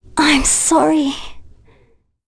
Ophelia-Vox_Dead.wav